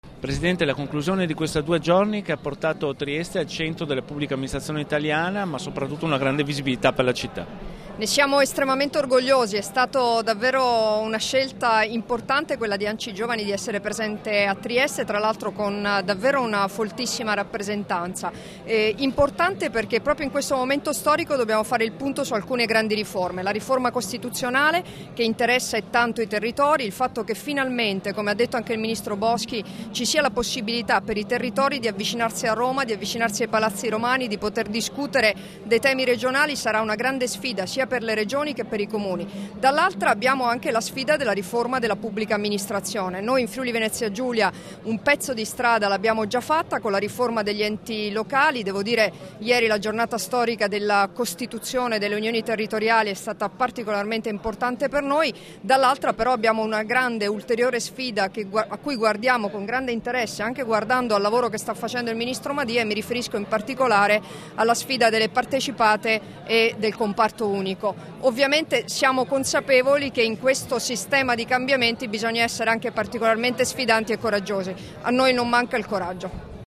Dichiarazioni di Debora Serracchiani (Formato MP3) [1227KB]
a margine della settima assemblea nazionale ANCI Giovani, rilasciate a Trieste il 16 aprile 2016